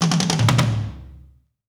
British SKA REGGAE FILL - 08.wav